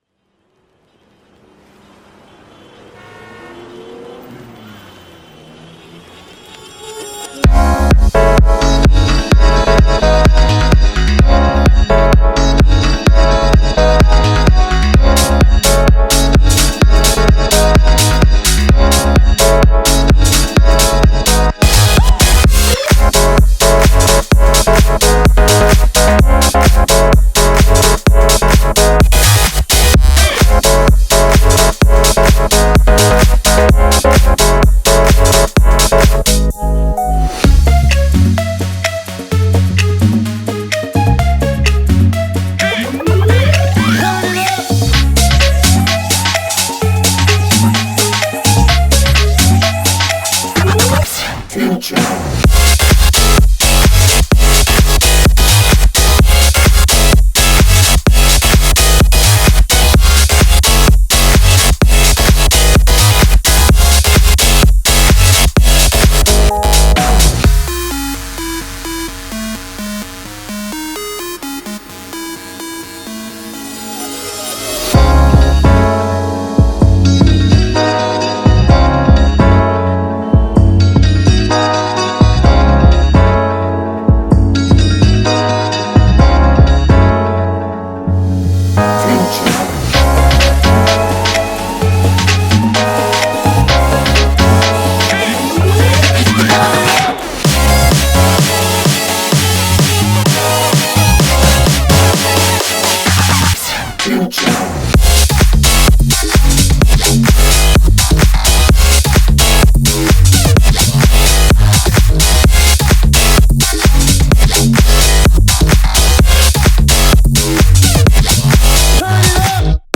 BPM64-128
Audio QualityPerfect (High Quality)